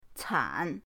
can3.mp3